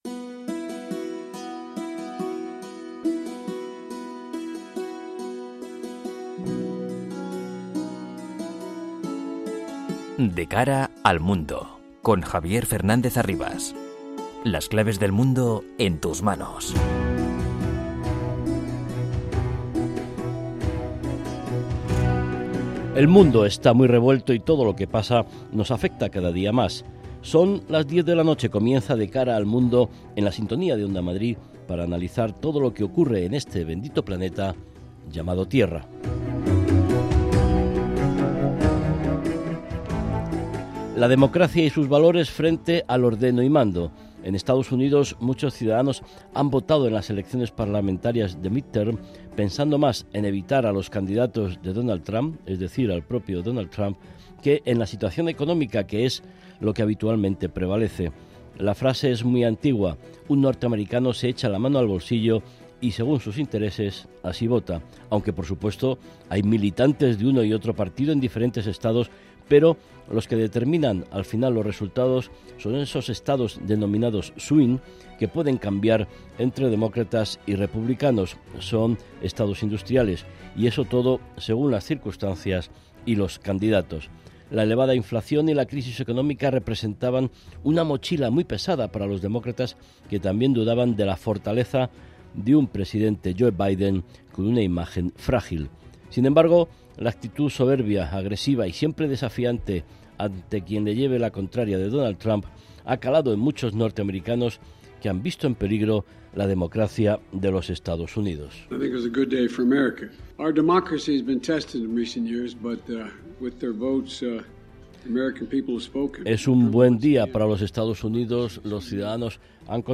analiza los principales sucesos en el panorama internacional con entrevistas a expertos y un panel completo de analistas.